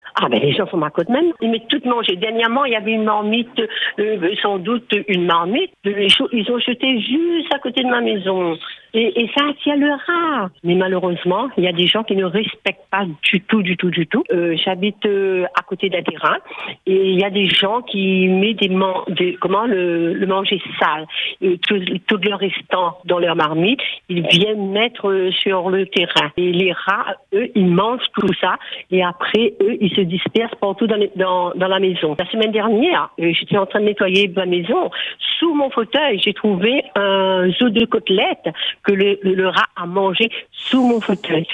Podcast – « Rats à La Palissade » : une habitante de Saint-Louis n’en peut plus